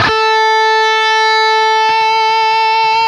LEAD A 3 CUT.wav